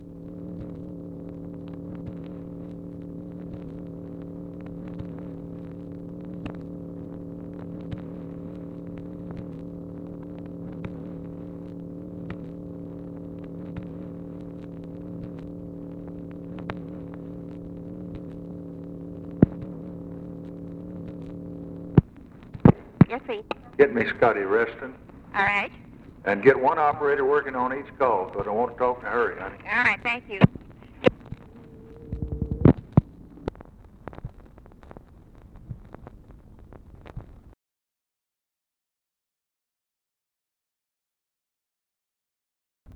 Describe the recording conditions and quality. Secret White House Tapes